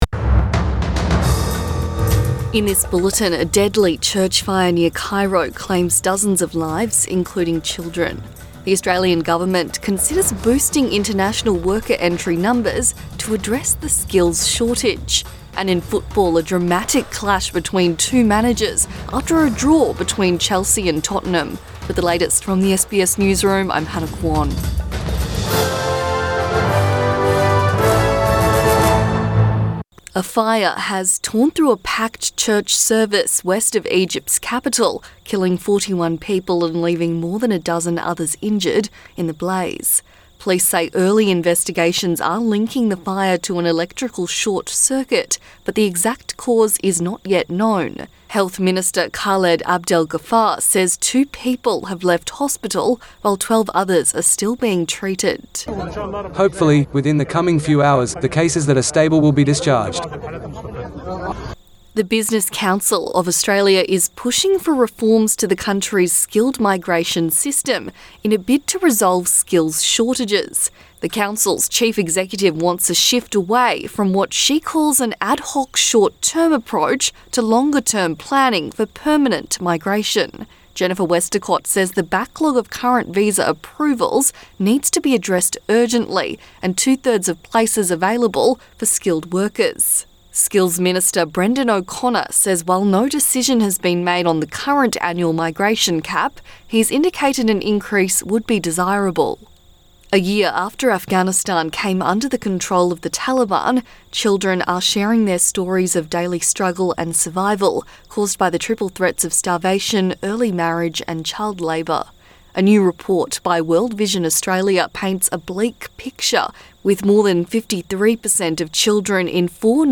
AM bulletin 15 August 2022